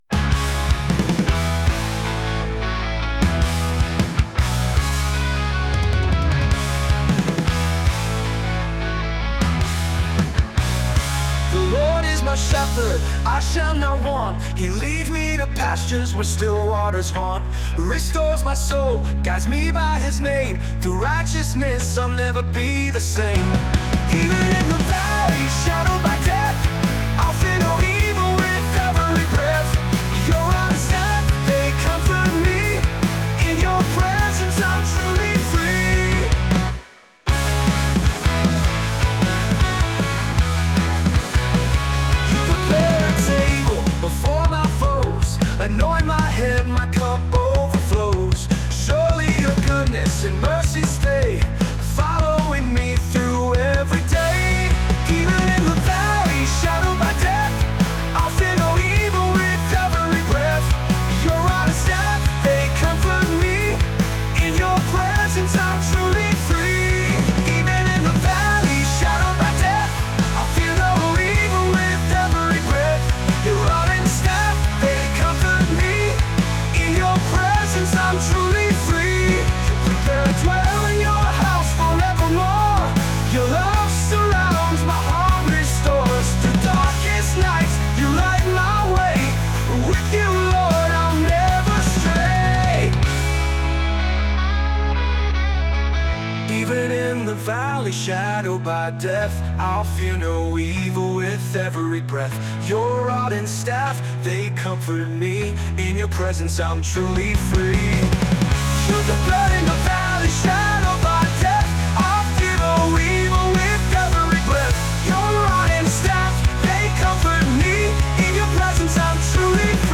Christian Praise